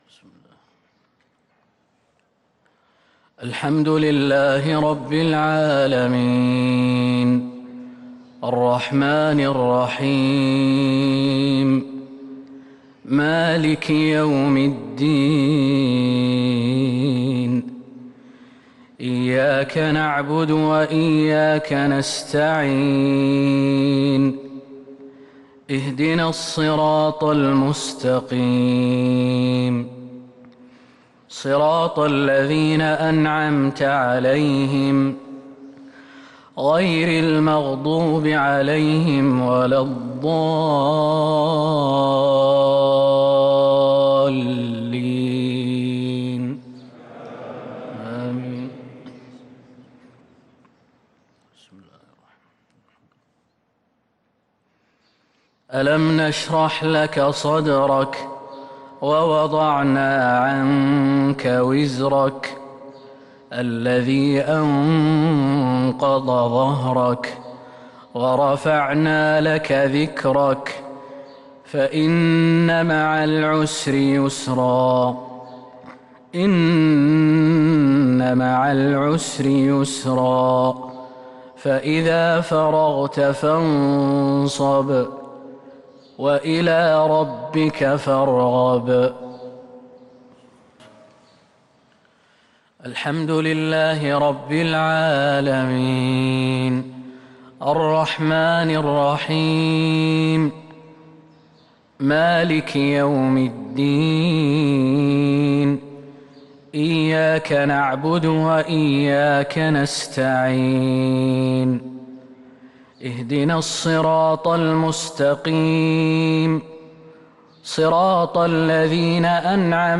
مغرب الخميس 5-5-1443هـ سورتي الشرح والكوثر | maghrib prayer from Surah Ash-Sharh and Al-Kawthar 9/12/2021 > 1443 🕌 > الفروض - تلاوات الحرمين